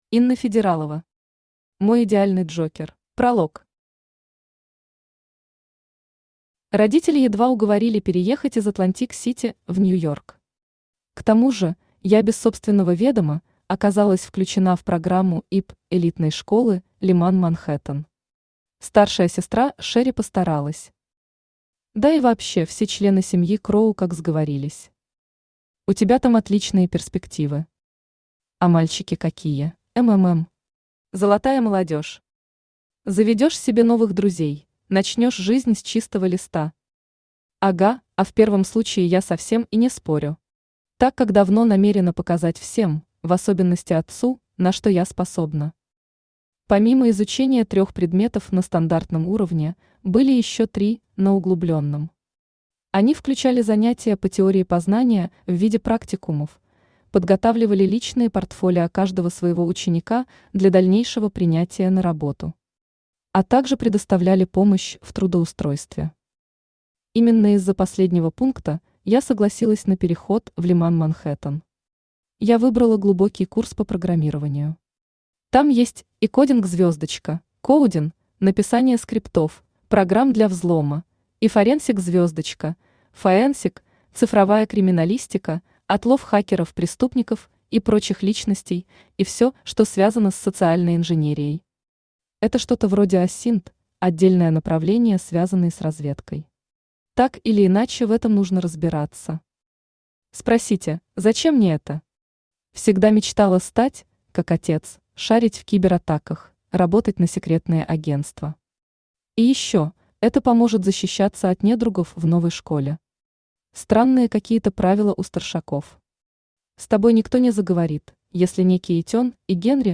Aудиокнига Мой идеальный Джокер Автор Инна Федералова Читает аудиокнигу Авточтец ЛитРес.